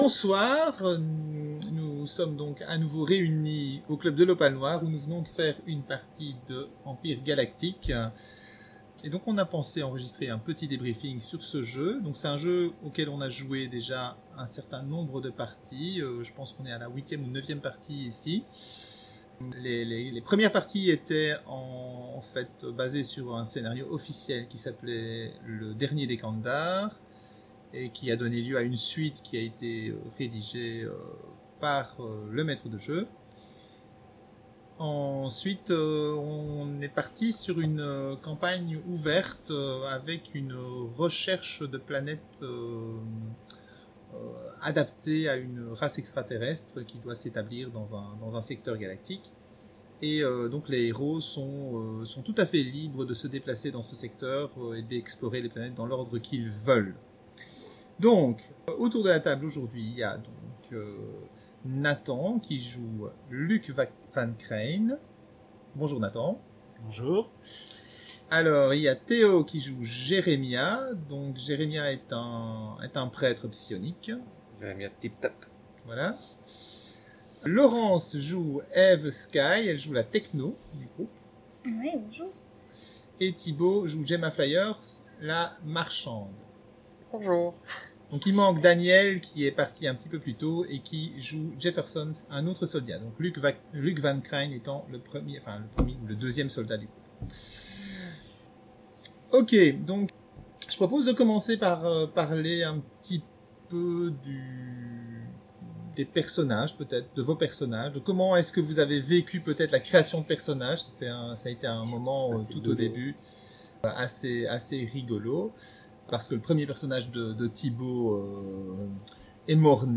J'ai enregistré un petit débriefing après notre dernier scénario d'Empire Galactique.